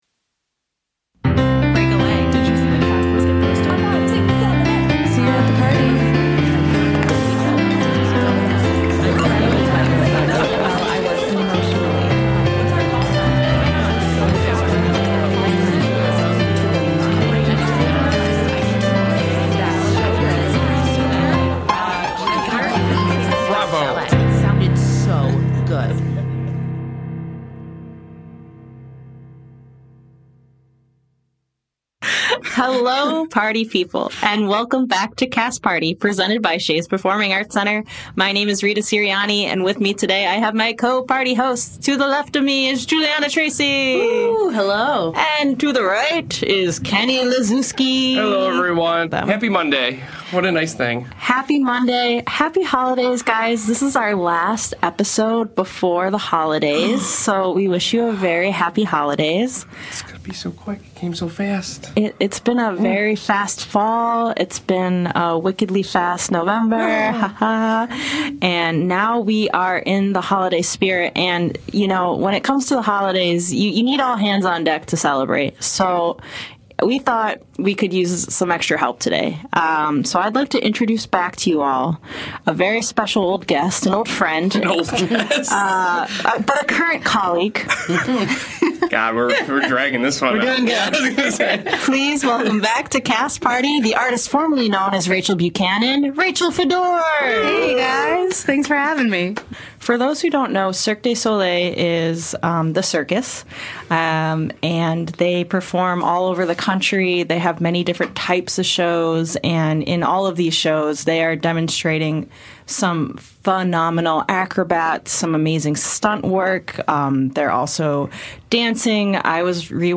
Plus — a Cast Party first — the gang delivers a special dramatic holiday reading of a poem that might sound a little familiar.